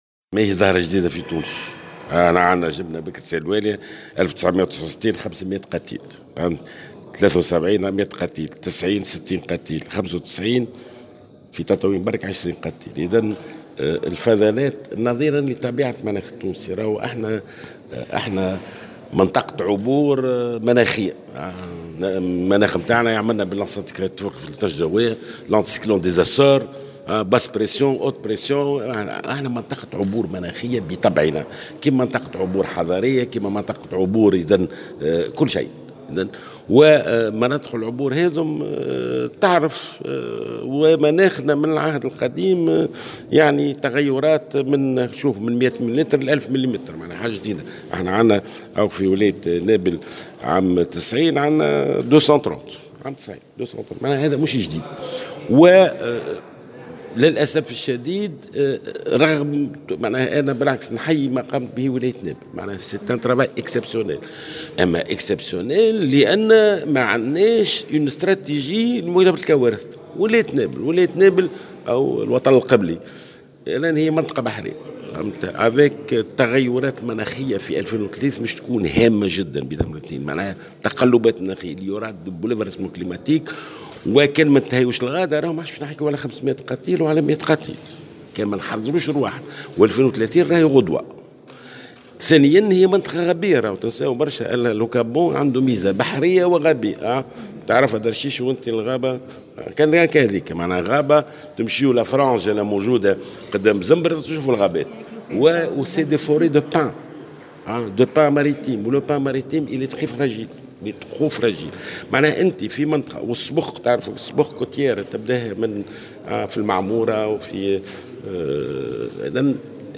وجاء ذلك على هامش ندوة مشتركة بين ولاية نابل و المعهد التونسي للدراسات الإستراتيجية حول إدارة الأزمات والطوارئ باعتماد تجربة فيضانات نابل سبتمبر 2018 نموذجا لإعداد إجراءات التعامل مع الأزمات والحالات الطارئة.